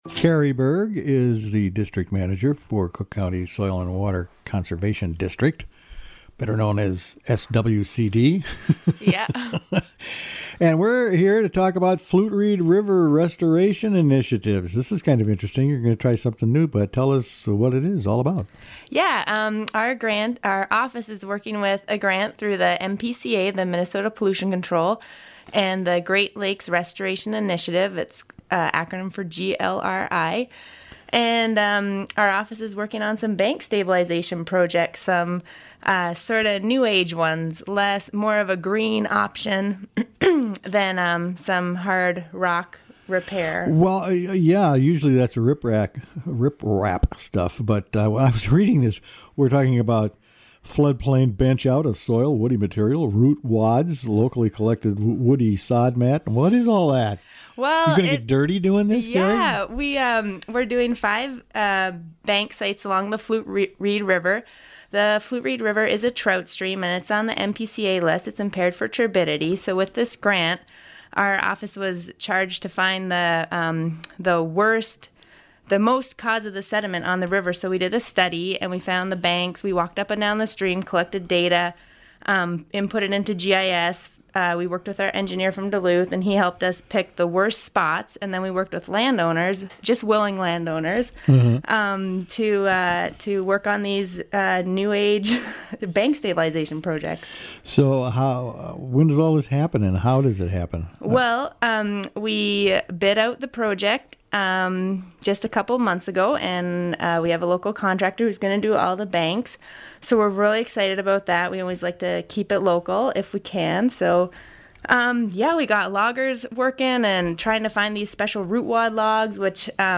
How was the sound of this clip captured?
Erosion mitigation to start on Flute Reed | WTIP North Shore Community Radio, Cook County, Minnesota